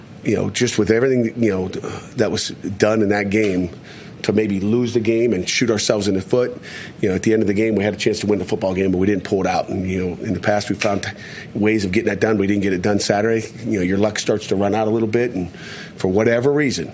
Coach Pat Narduzzi said the Panthers did everything they could to “shoot themselves in the foot,” against Virginia.